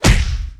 PunchHit4.wav